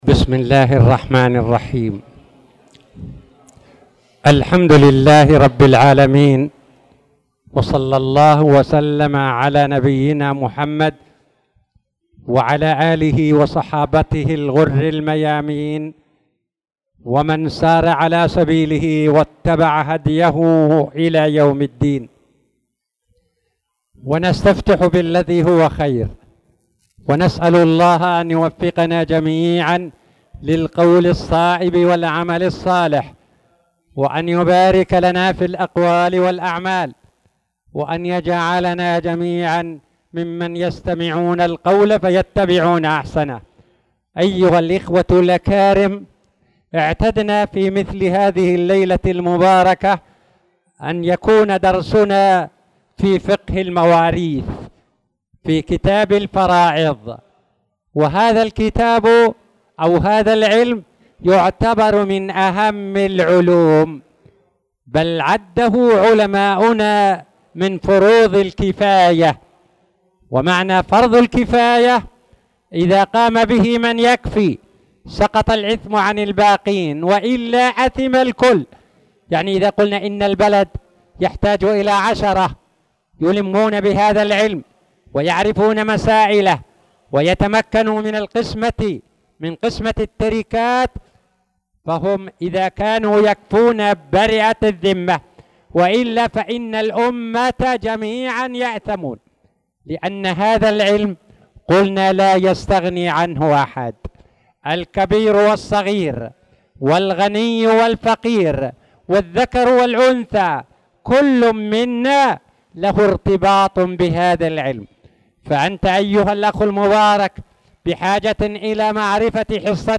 تاريخ النشر ٢٣ شعبان ١٤٣٧ هـ المكان: المسجد الحرام الشيخ